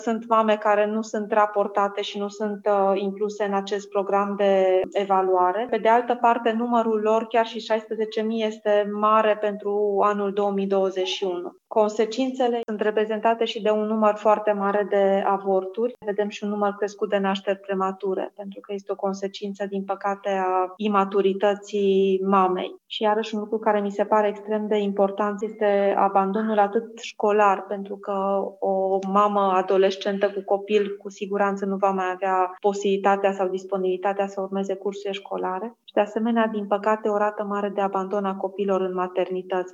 Ea a participat, marți, la o dezbatere organizată de Salvații Copiii, inititulată “România are cele mai multe nașteri din Europa la mame având vârsta sub 15 ani: ce trebuie făcut?”.
Andreea Moldovan a prezentat rezultatele unui studiu realizat de Institutul pentru Sănătatea Mamei și Copilului, INSP și Universitatea Carol Davila: